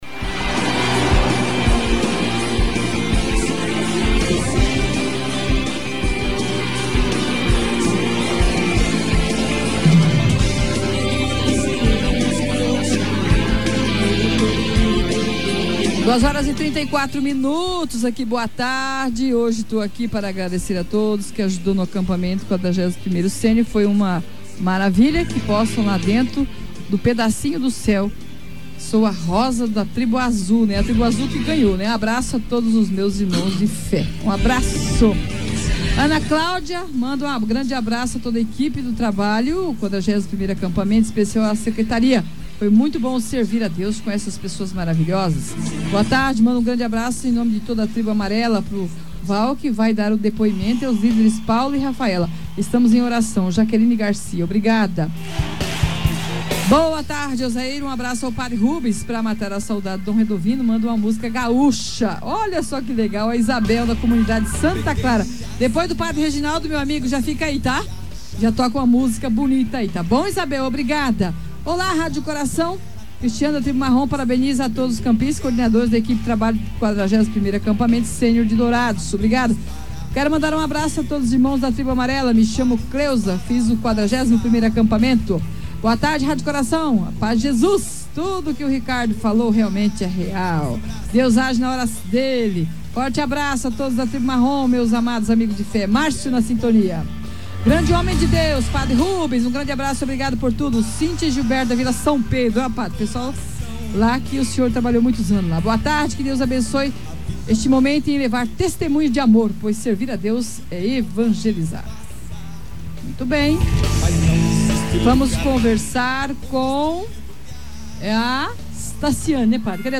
Ouça: testemunhos dos campistas do 41º sênior
Nesta quarta-feira (06/11), participantes e líderes do 41º Acampamento Sênior, do Santuário Padre Pio, partilharam em edificantes e emocionantes testemunhos, a experiência que tiveram neste acampamento.